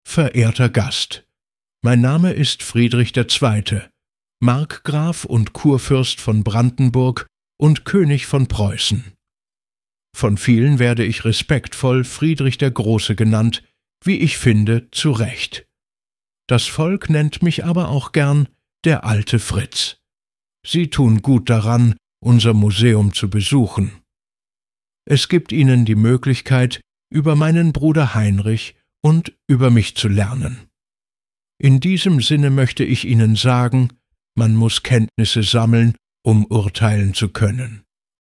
Persönliche Begrüßung
elevenlabs_text_to_speech_audio-1-.mp3